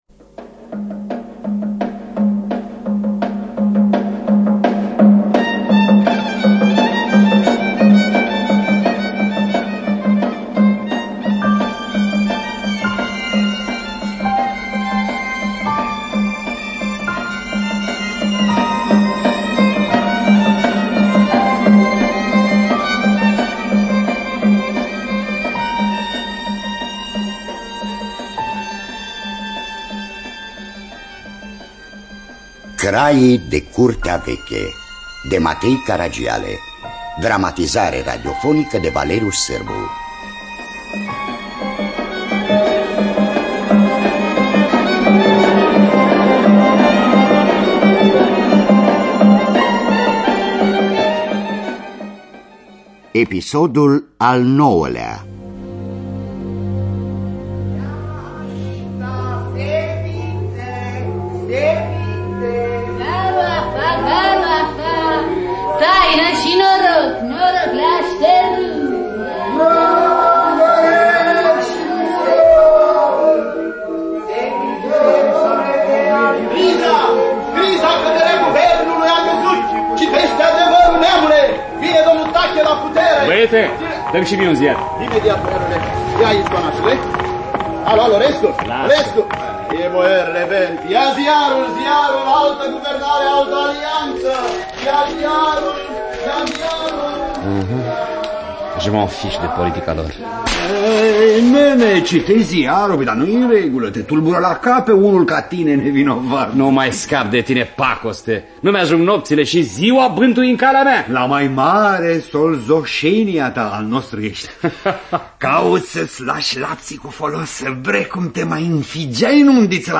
Dramatizarea radiofonică
În distribuţie: Adrian Pintea, Constantin Codrescu, Ion Caramitru.